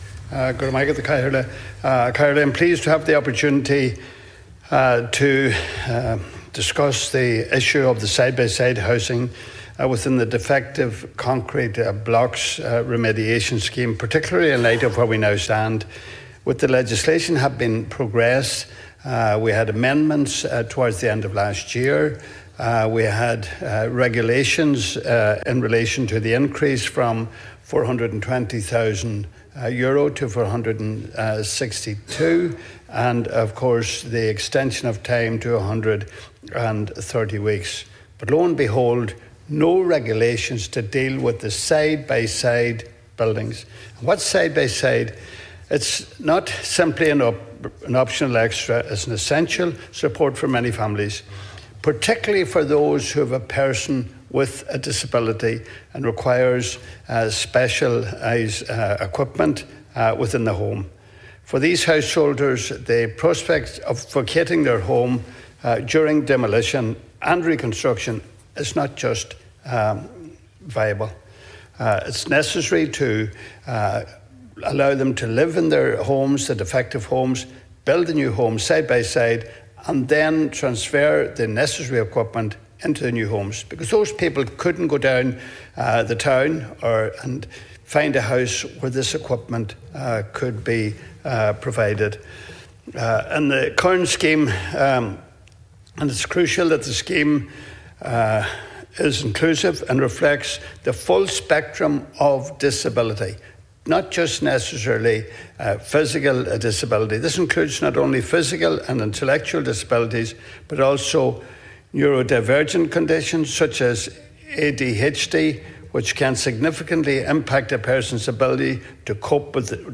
The issue of side-by-side building within the Defective Concrete Block Scheme was raised during a sitting of the Dáil last evening.
Deputy Gallagher says that it is a matter of urgency that there is flexibility within regulation to help families: